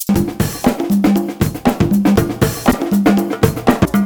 133SHAK03.wav